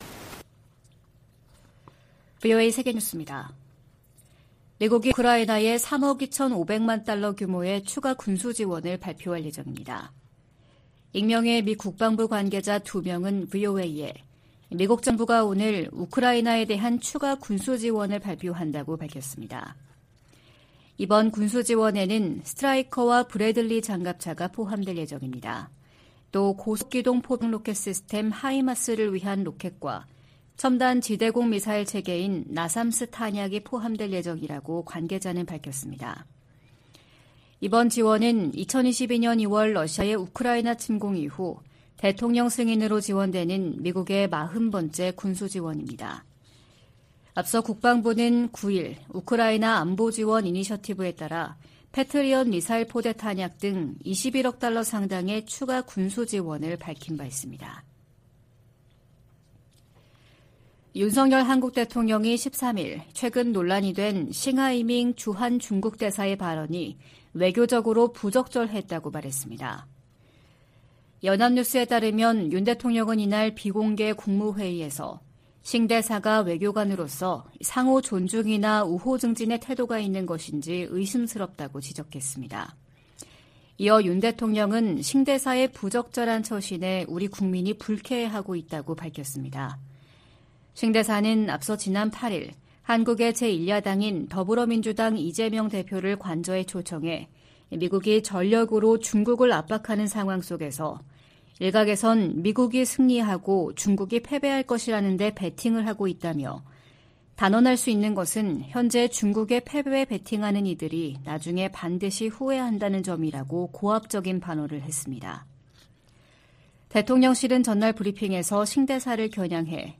VOA 한국어 '출발 뉴스 쇼', 2023년 6월 14일 방송입니다. 미국의 북핵 수석 대표는 워싱턴에서 한국의 북핵 수석대표와 회담한 후 북한의 추가 도발에 독자제재로 대응할 것이라는 입장을 밝혔습니다. 북한은 군사정찰위성 추가 발사 의지를 밝히면서도 발사 시한을 미리 공개하지 않겠다는 입장을 보이고 있습니다.